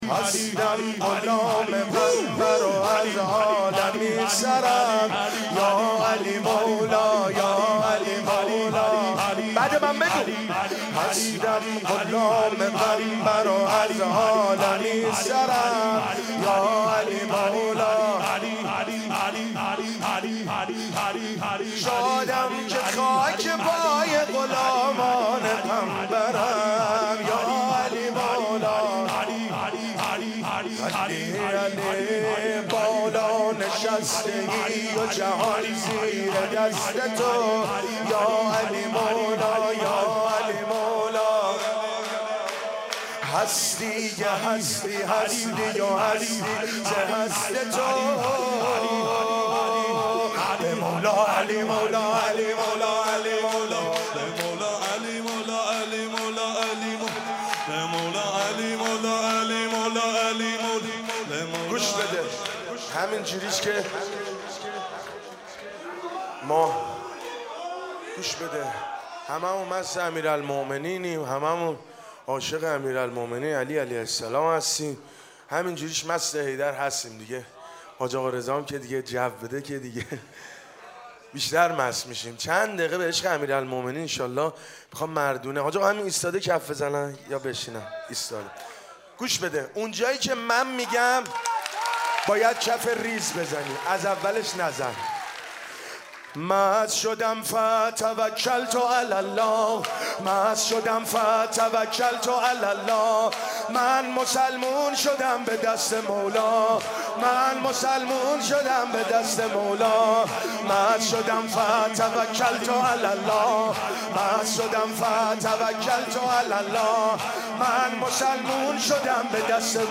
عقیق: جشن عید ولایت و امامت، عید سعید غدیر خم در هیئت الرضا (ع)، محفل بسیجیان و رهروان شهدا واقع در میدان خراسان، حسینیه امام موسی بن جعفر (ع) برگزار شد.